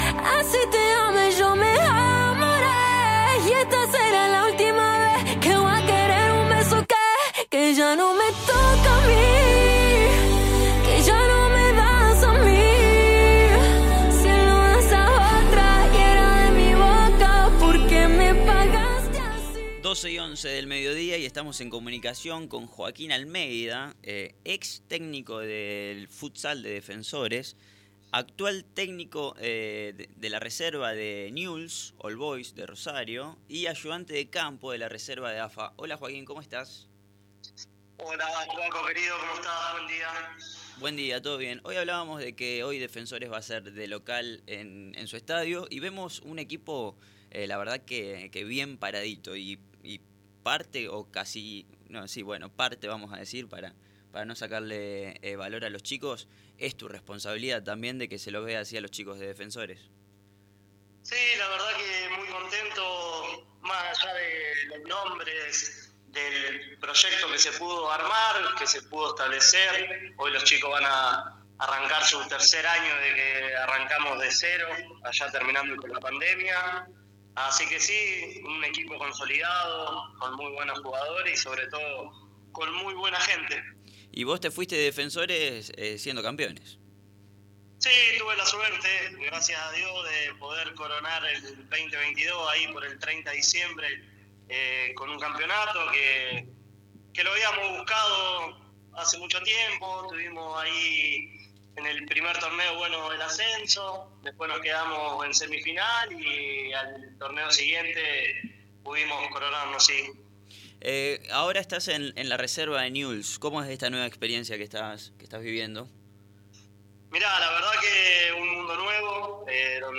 Hoy en comunicación con los estudios de la NBA